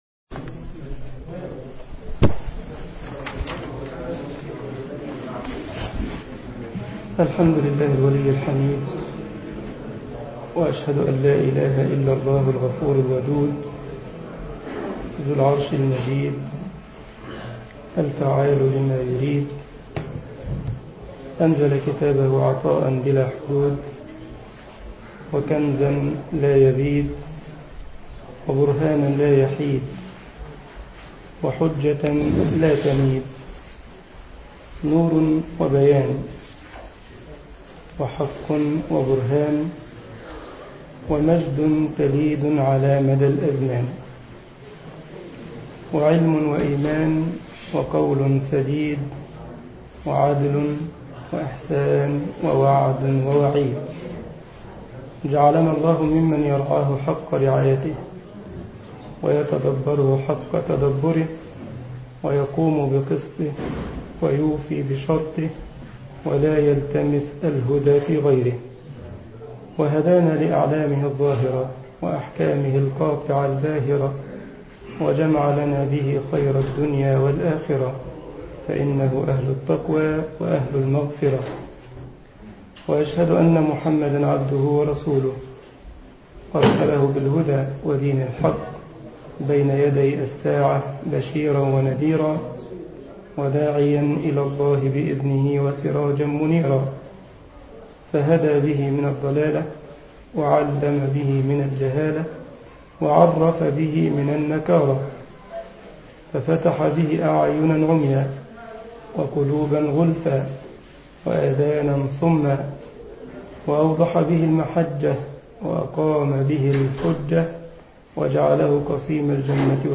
مسجد كايزرسلاوترن ـ ألمانيا محاضرة